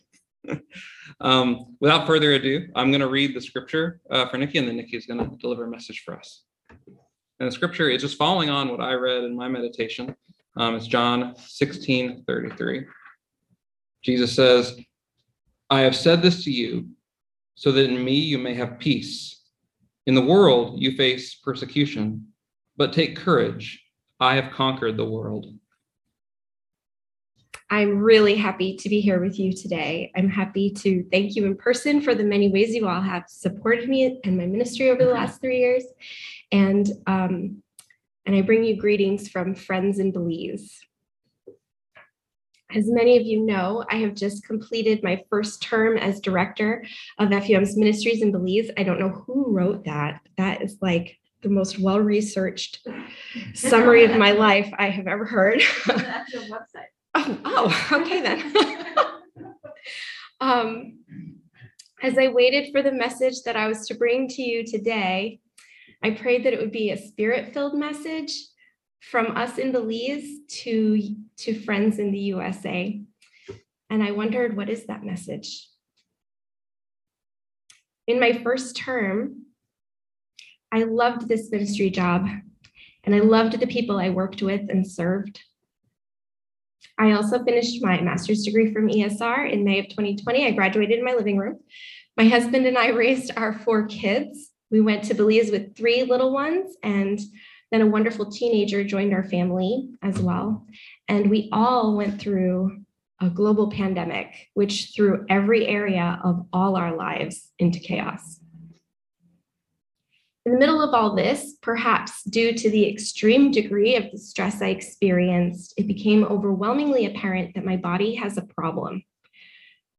Message for July 3, 2022